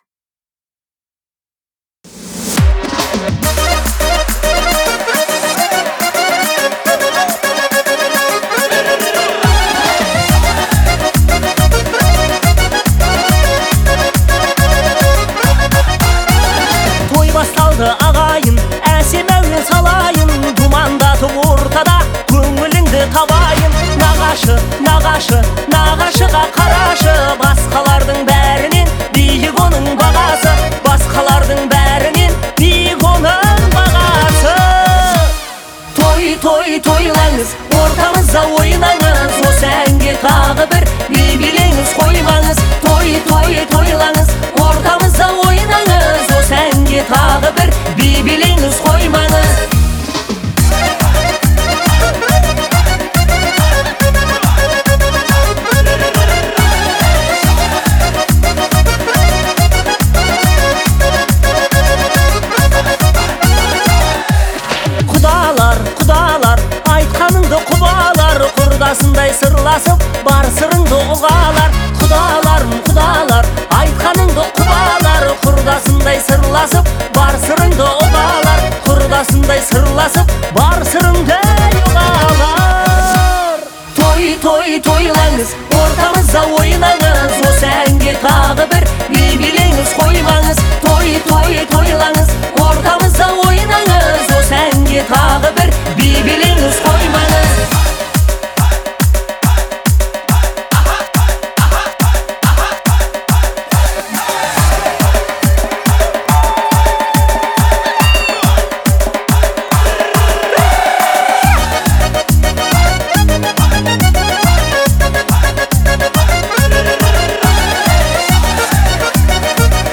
это яркая и мелодичная песня в жанре казахской поп-музыки